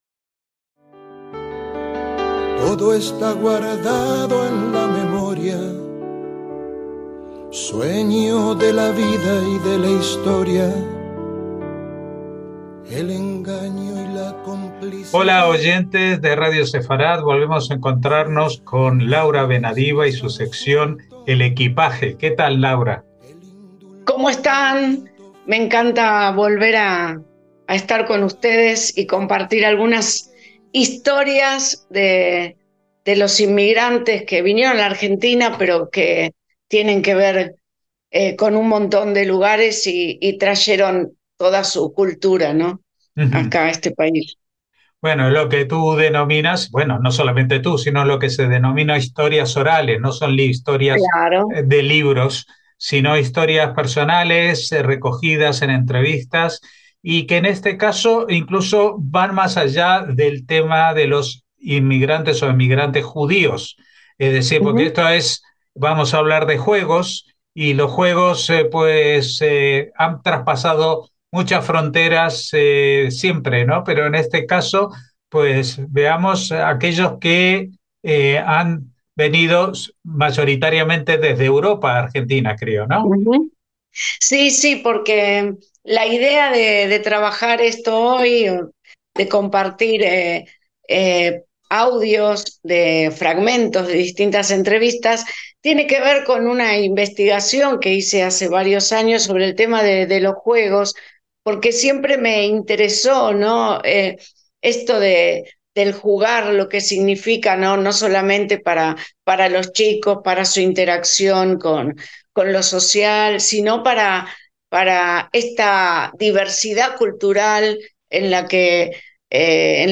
Presentamos entrevistas de Historia Oral de maestras que reviven con los niños los juegos que sus abuelos trajeron de sus países de origen. Estos juegos no solo entretienen, sino que también sirven como puente entre generaciones y culturas, preservando tradiciones y creando nuevos lazos en la comunidad.